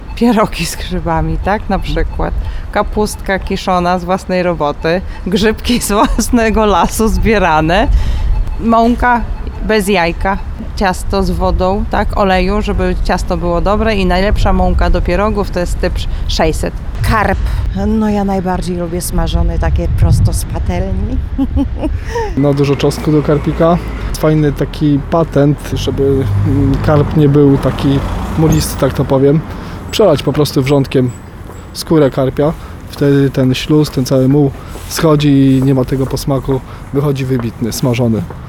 Zapytaliśmy przechodniów na ulicach Suwałk, czy znają jakieś przepisy na dania wigilijne.